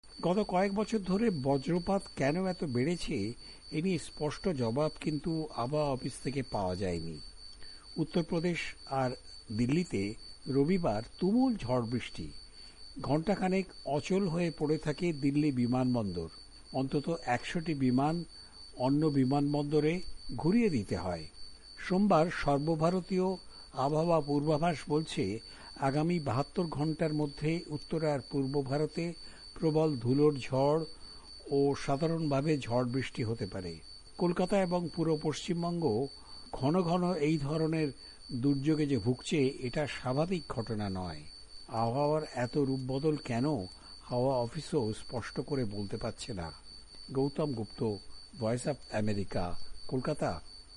রিপোর্ট।